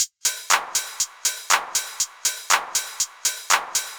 Index of /musicradar/retro-house-samples/Drum Loops
Beat 03 No Kick (120BPM).wav